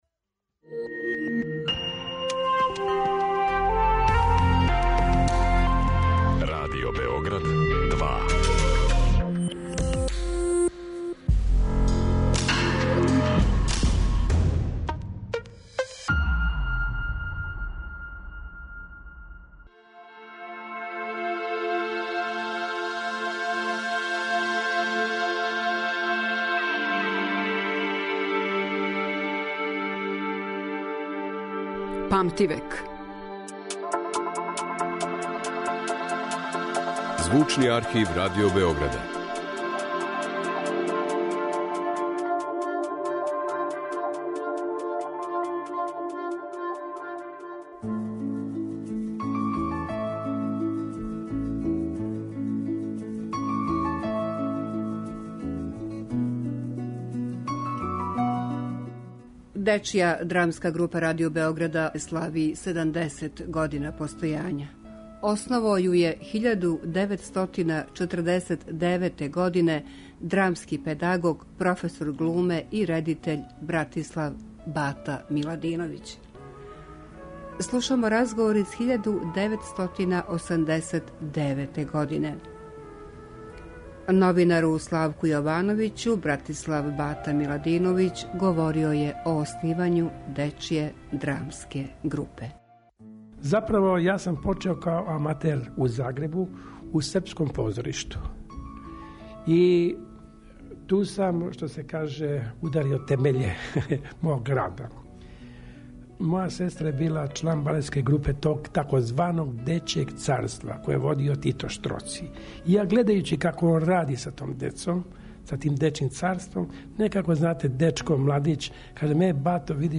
Емисија која ће покушати да афирмише богатство Звучног архива Радио Београда, у коме се чувају занимљиви, ексклузивни снимци стварани током целог једног века, колико траје историја нашег радија.